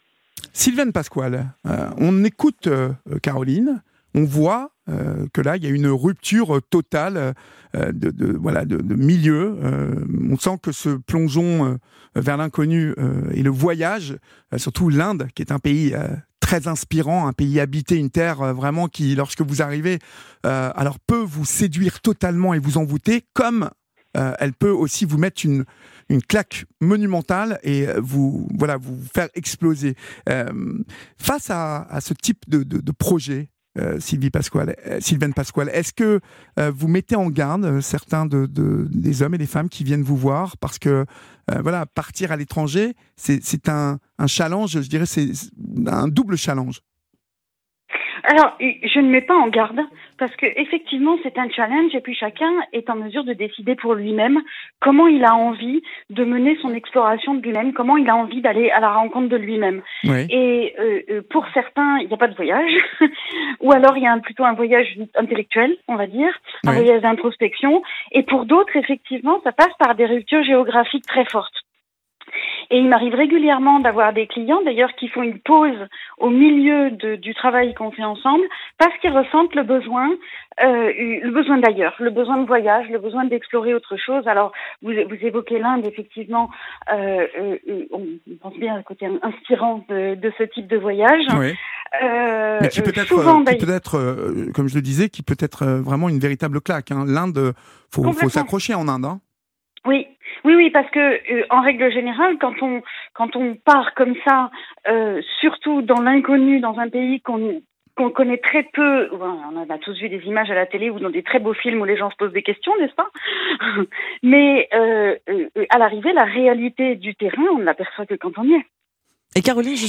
J’ai été l’invitée d’Olivier Delacroix dans l’émission Partageons nos expériences de vie sur Europe 1 sur le thème Tout plaquer du jour au lendemain